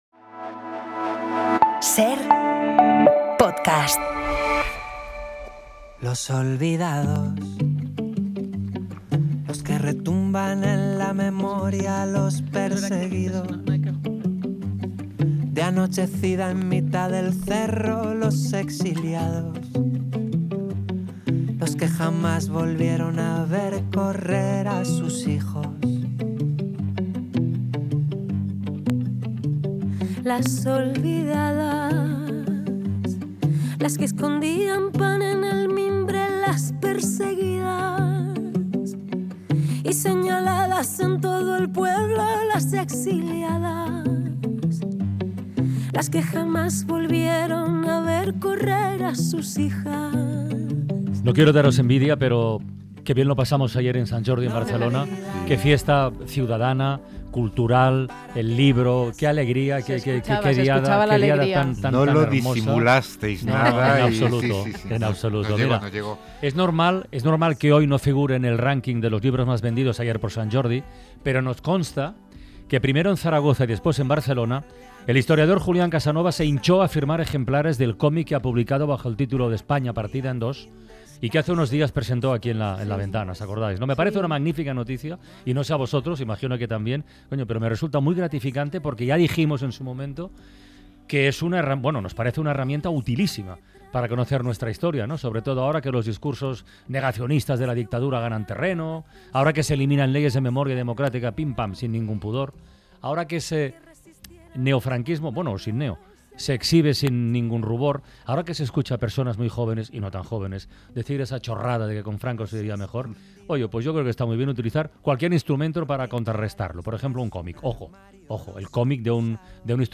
“La bala”: el nexo entre los caídos de la División Azul y las fosas del franquismo 22:53 SER Podcast El director Carlos Iglesias explica en 'La Ventana' cómo esta película iguala la memoria de vencedores y vencidos de la Guerra Civil.